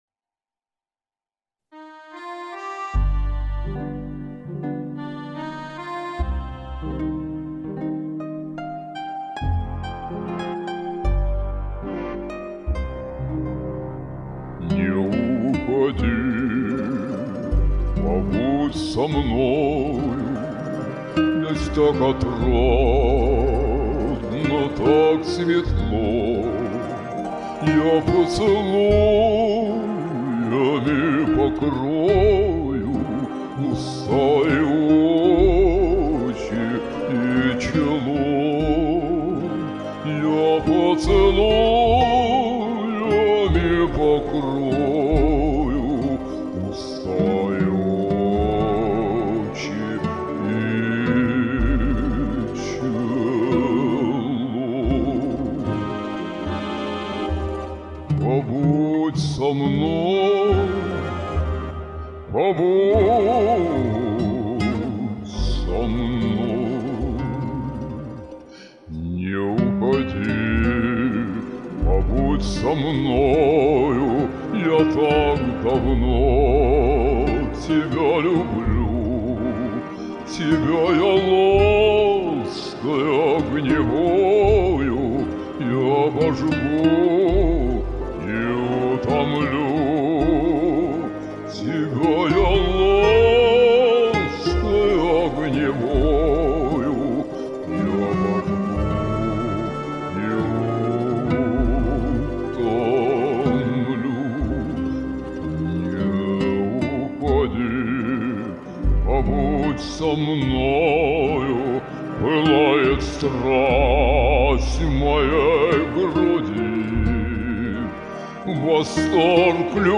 Разные голоса, но каждый спел отлично!
Ваша мольба , такая немножко печальная, будто Вы и не верите уже в то, о чем